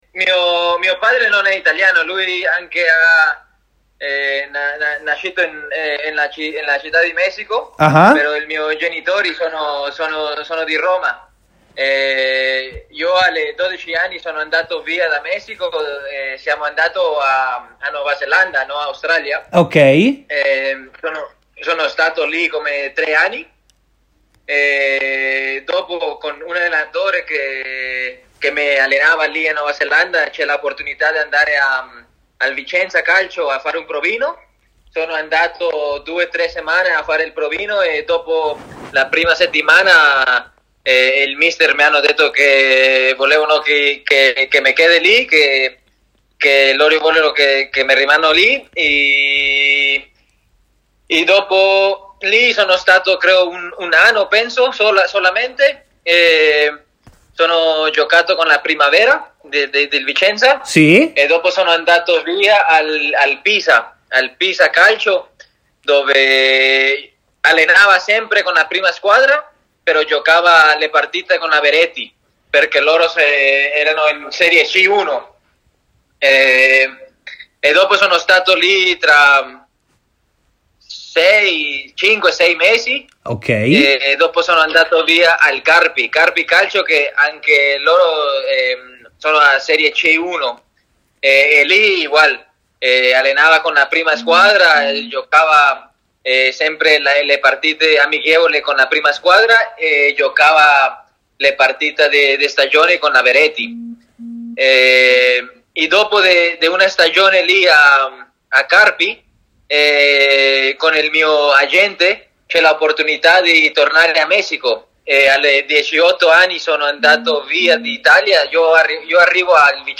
è intervenuto in diretta a TMW Radio